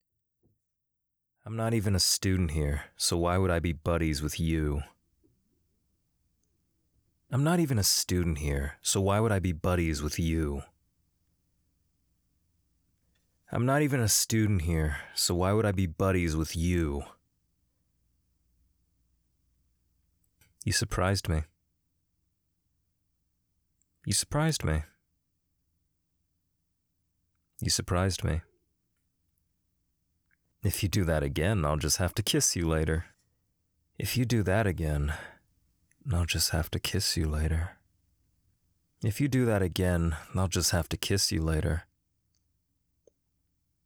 Voice: Deep, young, masculine, slightly monotone/uncaring about everything.